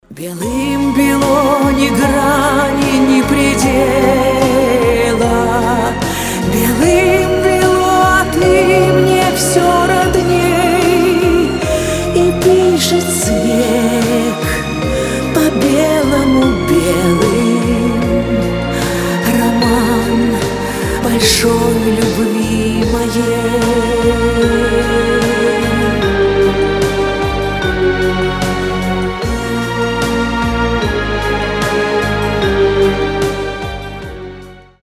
романтические
медленные